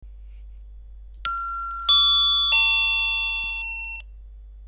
• počet melódií: 3 (Ding Dong, Ding Dong Dong, Big Ben)
Bezdrotovy-zvoncek-melodia-31.mp3